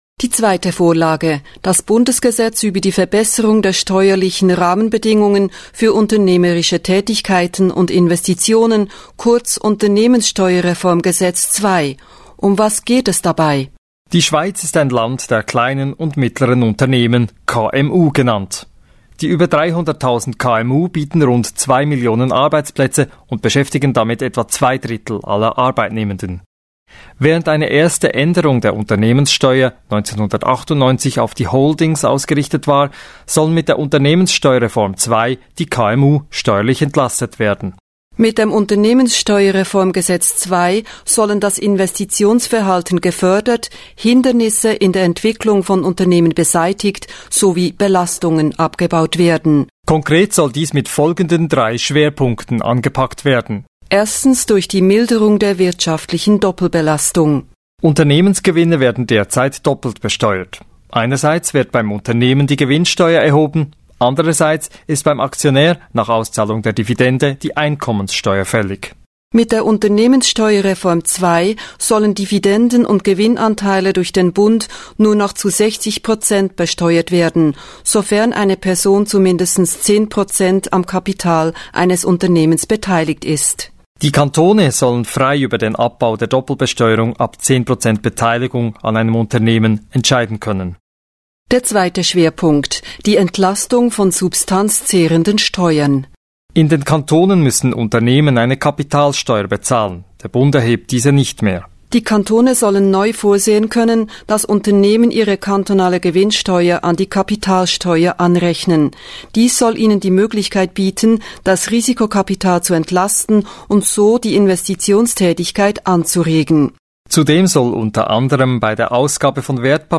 Und wir haben die wichtigsten Befürworter und Gegner zu einem Streitgespräch an einen Tisch geholt.